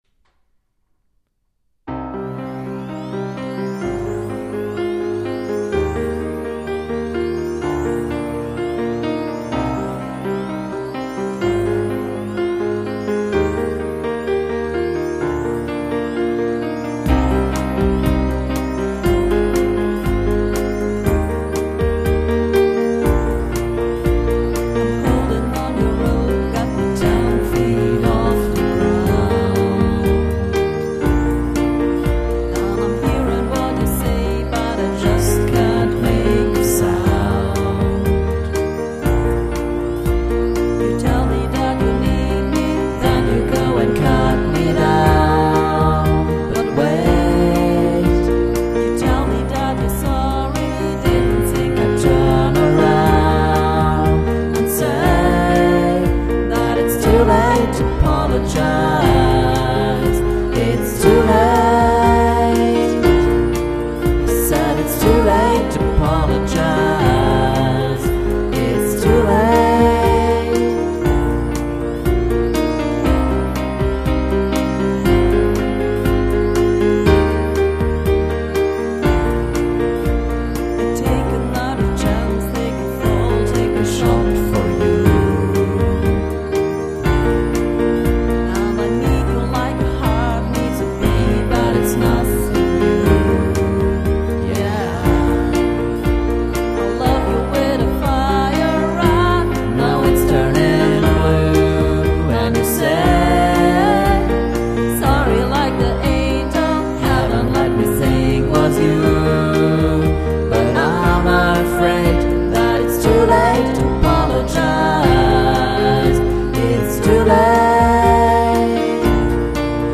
Hier findet ihr ein paar Aufnahmen von unseren Bandproben....